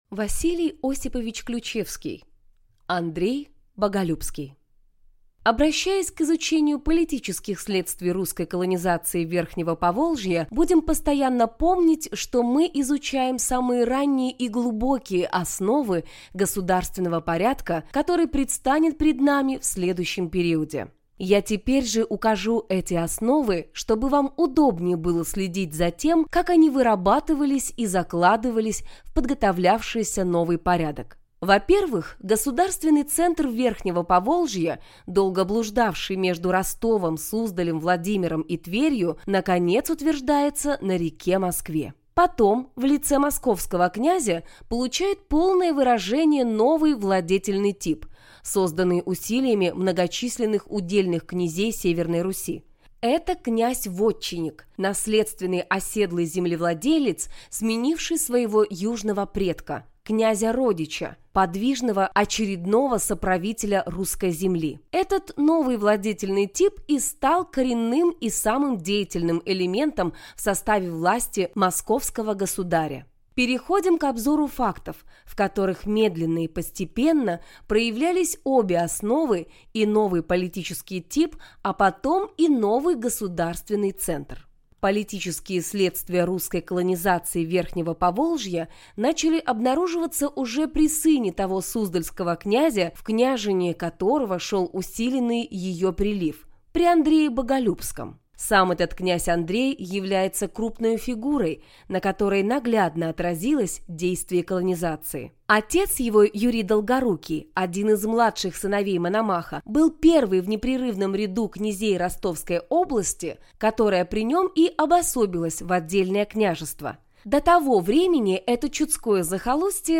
Аудиокнига Андрей Боголюбский | Библиотека аудиокниг